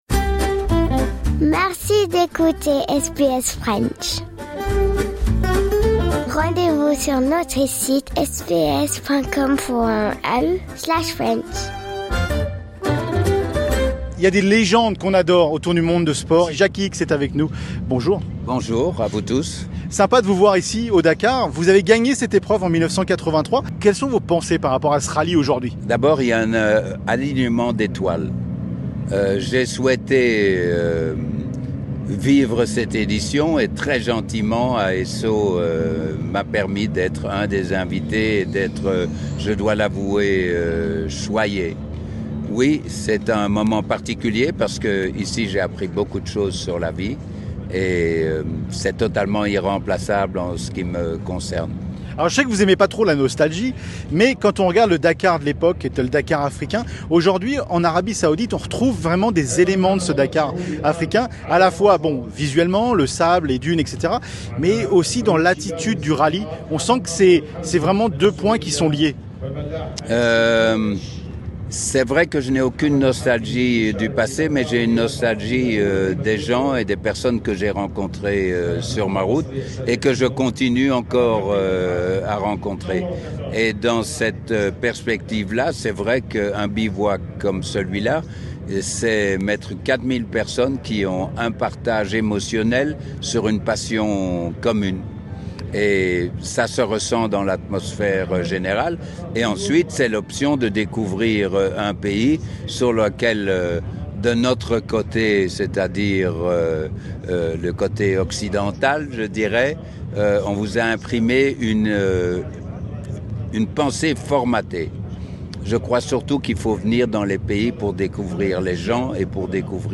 Nous avons eu l'honneur de discuter avec Jacky Ickx, une légende du sport automobile, lors de l'édition actuelle du rallye Dakar en Arabie Saoudite.